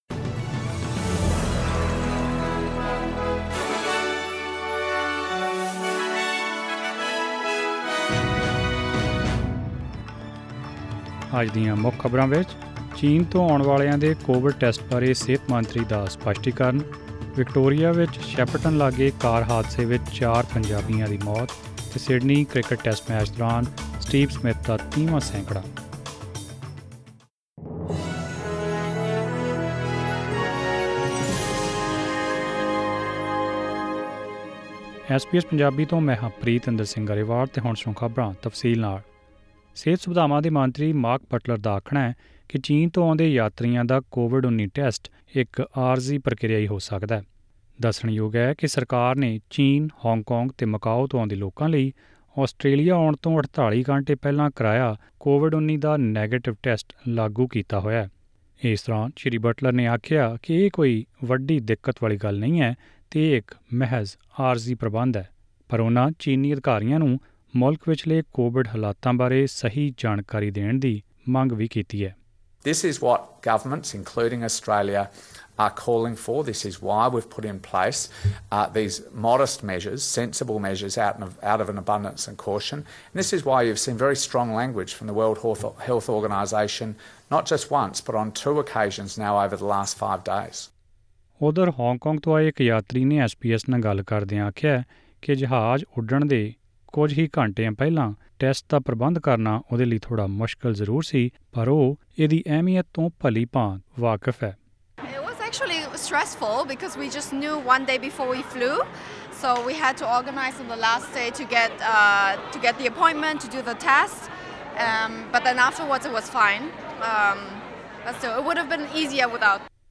Presenting the major national and international news stories of today; sports, currency exchange details and the weather forecast for tomorrow. Click on the audio button to listen to the full bulletin in Punjabi...